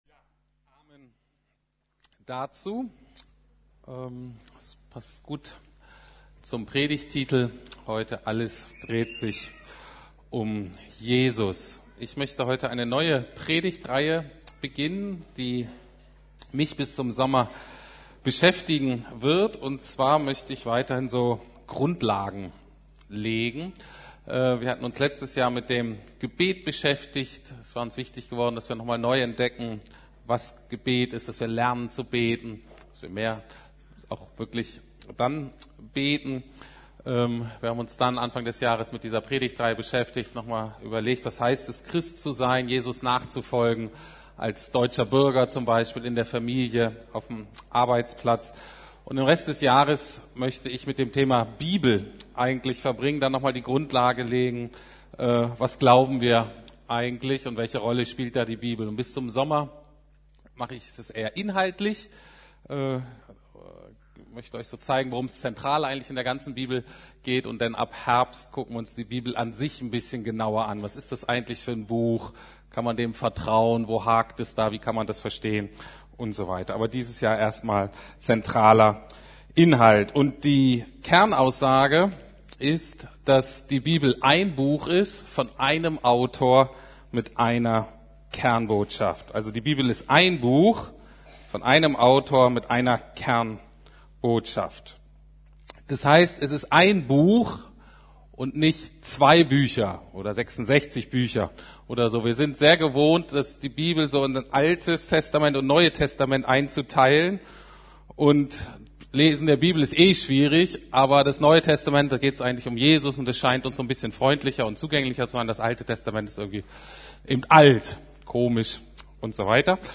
Jesus im Alten Testament: Es dreht sich alles um Jesus (Teil 1) ~ Predigten der LUKAS GEMEINDE Podcast